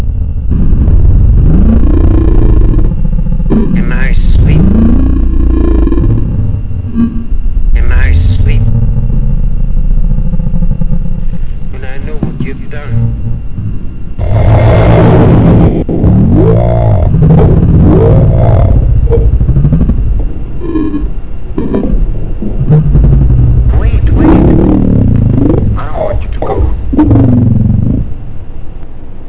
I hoped it would help speed download, unfortunately it did lower the quality, sorry!